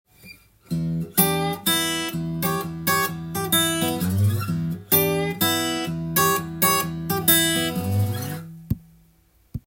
リズムに気をつけた場合
リズムの概念を入れてギターで弾いてみました。
リズムを気をつけて弾かない時に欠けていた何かがハマった感じがします。